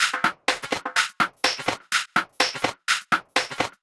tx_perc_125_warped2.wav